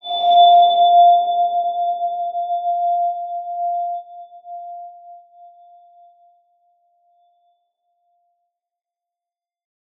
X_BasicBells-F3-mf.wav